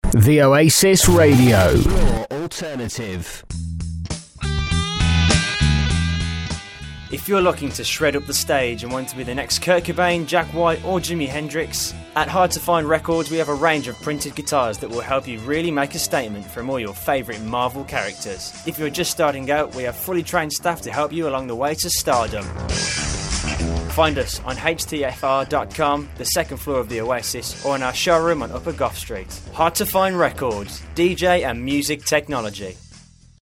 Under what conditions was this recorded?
Voice over work I did for Hard to Find Records, broadcast on The Oasis Radio in January 2012